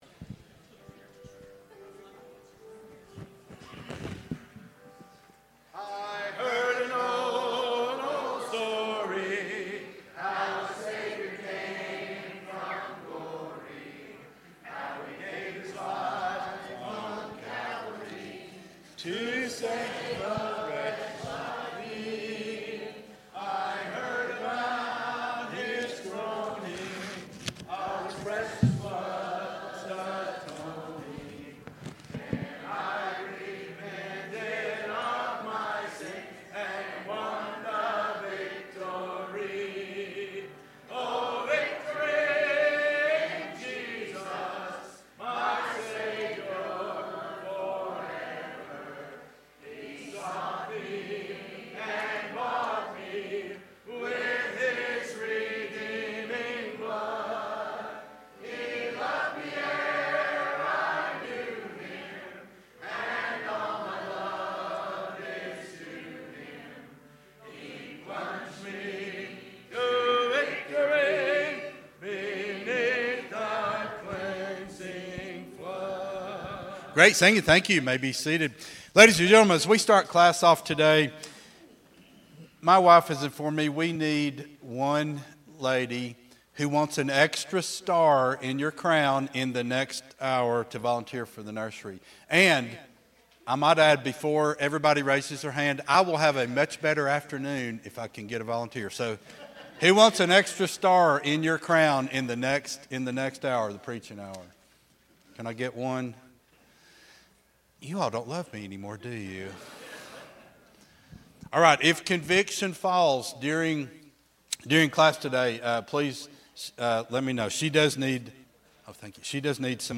Sunday School Lesson